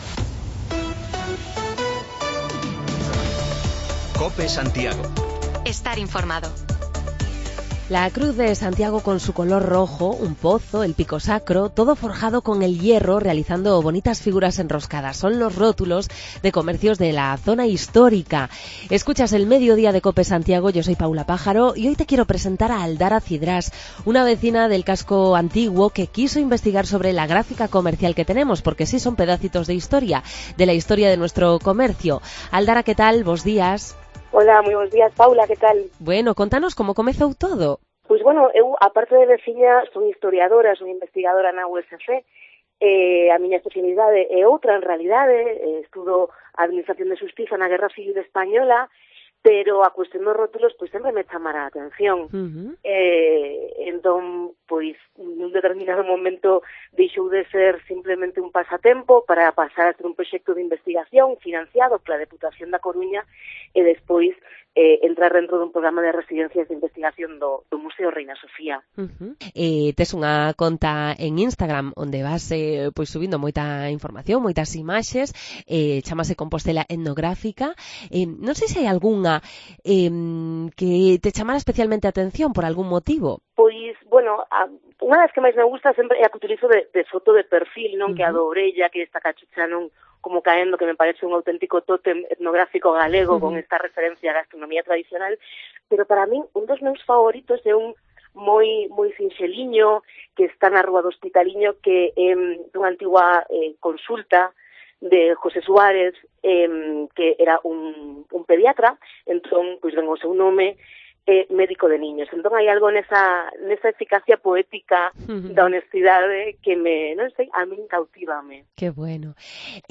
Varias personas mayores nos cuentan las dificultades que tienen para manejarse con las nuevas tecnologías, cada vez más presentes en nuestro día a día.